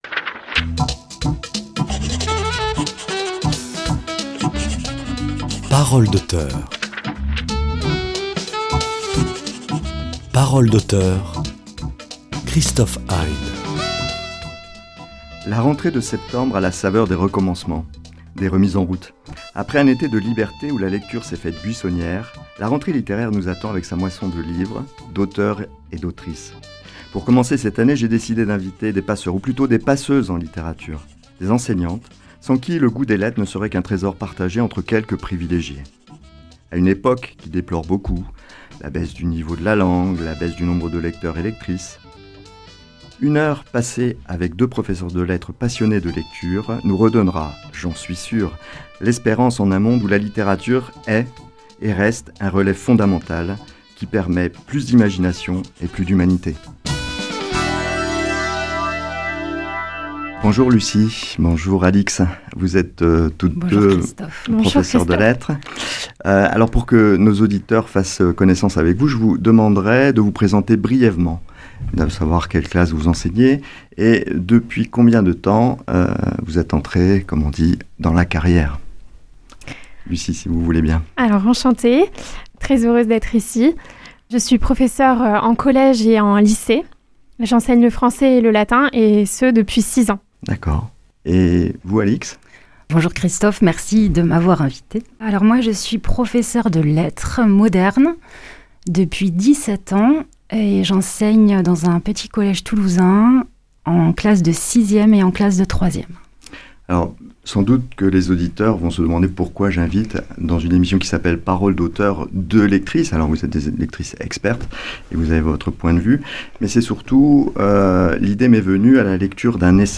À l'heure où les élèves reprennent le chemin de l'école, nous avons invité aux micros de notre émission deux professeures de lettres qui parlent de leur passion de la lecture.